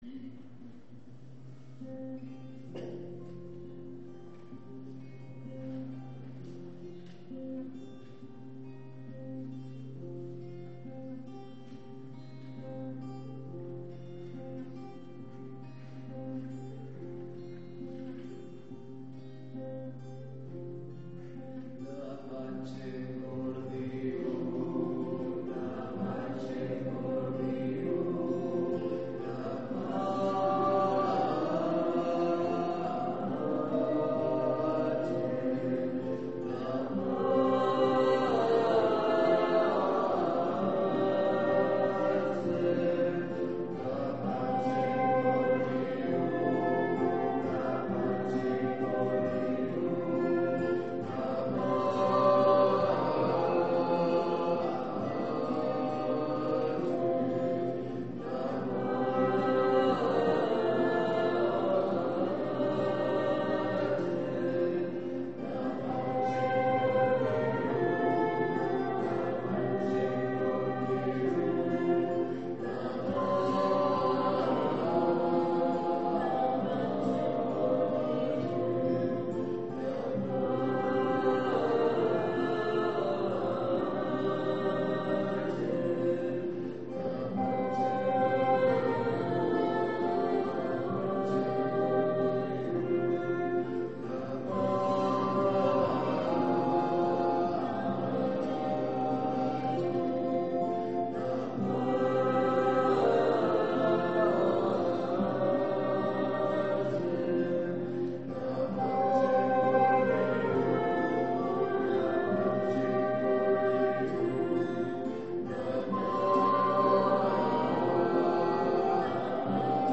Pregària de Taizé
Església de Santa Anna - Diumenge 28 d'octubre de 2012
Vàrem cantar...